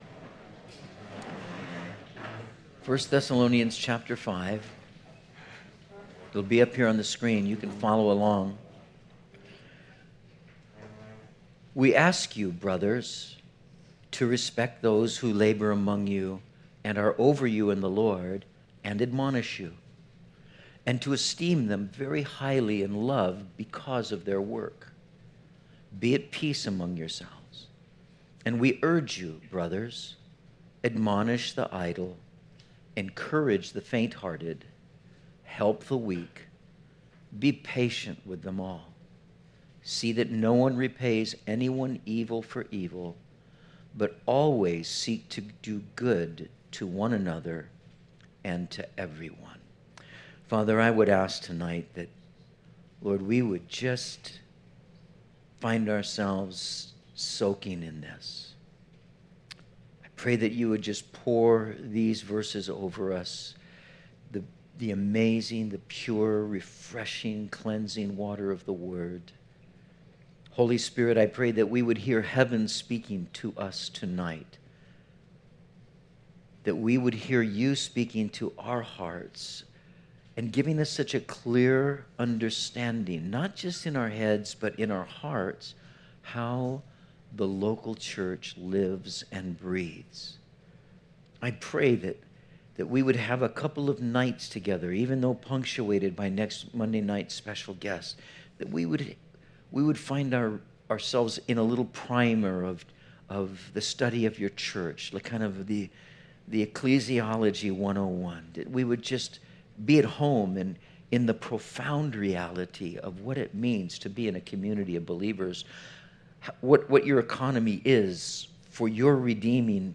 Content from Metro Calvary Sermons